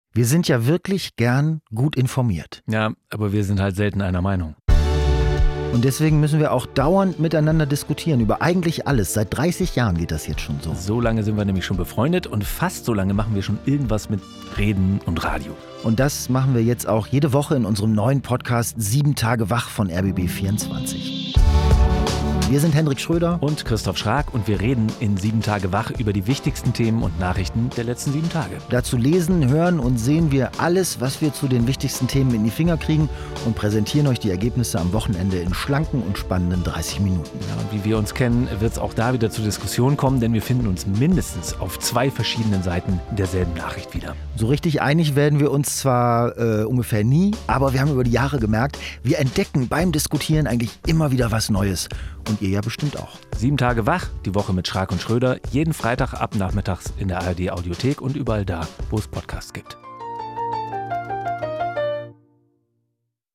Zwei Freunde, zwei Meinungen, ein News-Podcast: